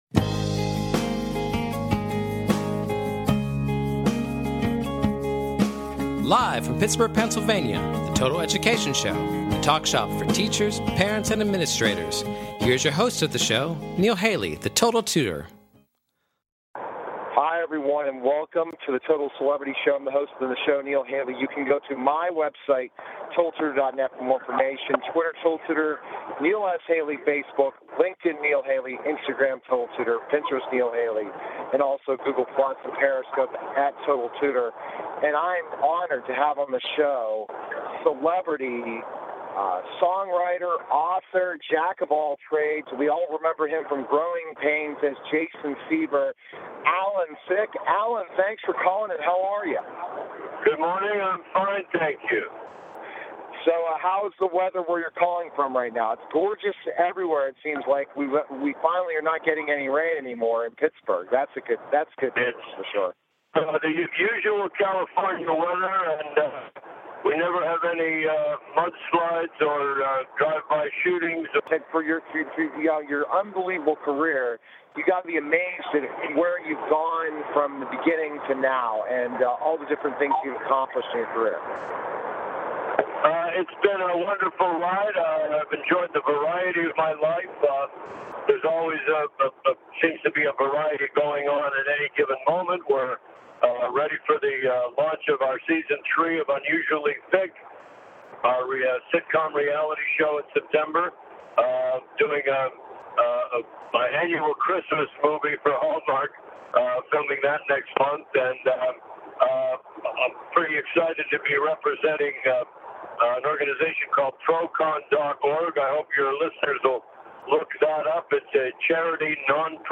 Talk Show Episode, Audio Podcast, Total_Education_Show and Courtesy of BBS Radio on , show guests , about , categorized as